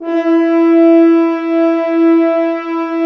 Index of /90_sSampleCDs/Roland L-CD702/VOL-2/BRS_F.Horns 2 f/BRS_FHns Dry f